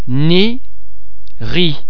The French [z] sound is normally pronounced [z] as in the English words zero, zebra etc.